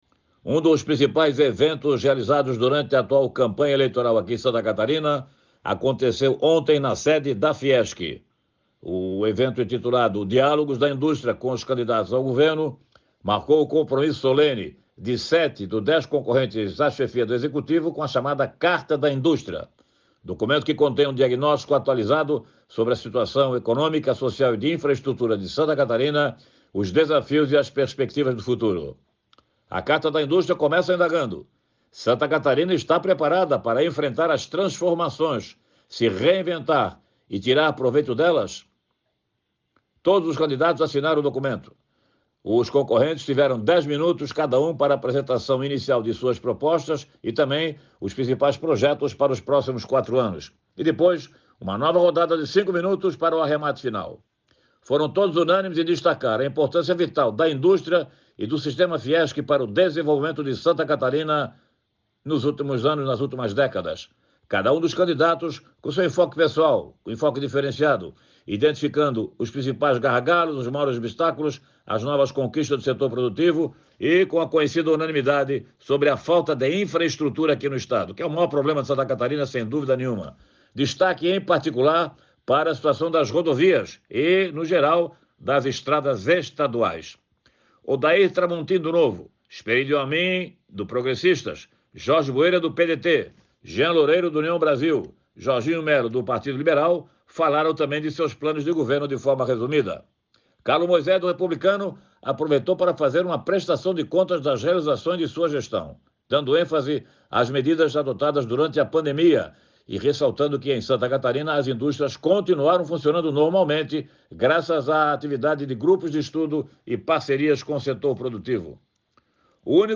Jornalista comenta que a entrega marcou o compromisso solene dos concorrentes à chefia do executivo com a indústria catarinense